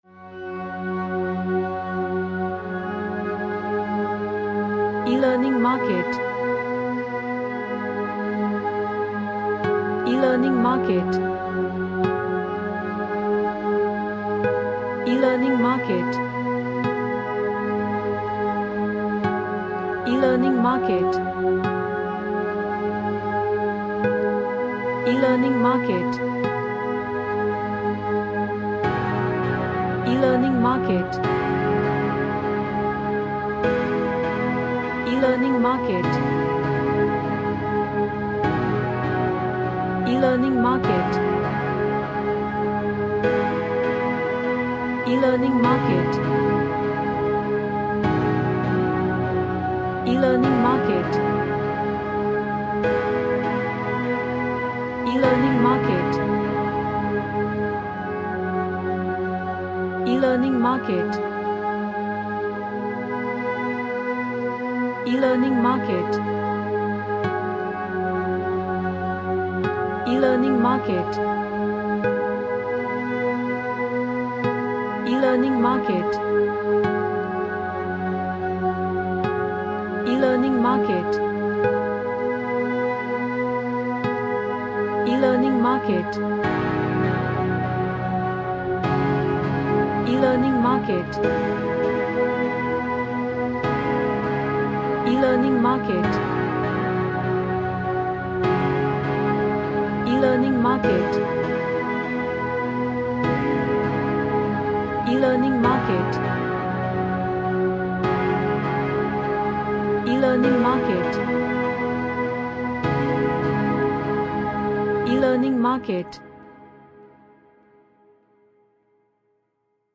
An Ambient Track with realxing vibe.
Relaxation / Meditation